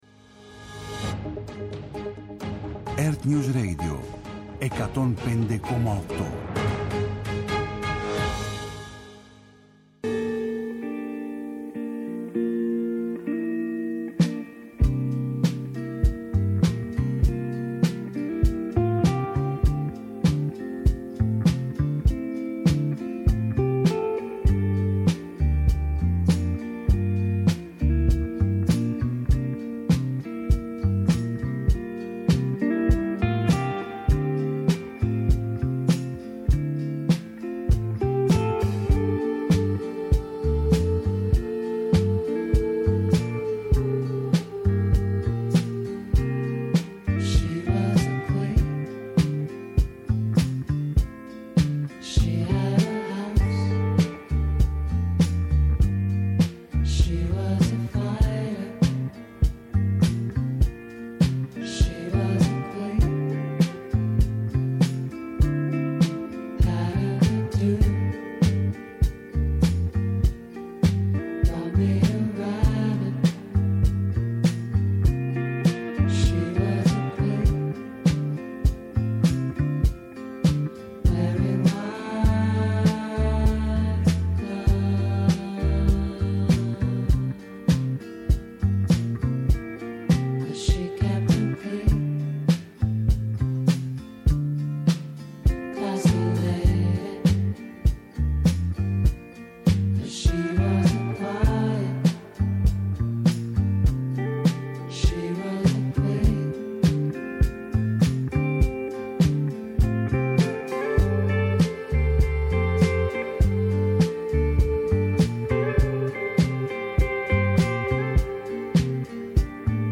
ΕΡΤNEWS RADIO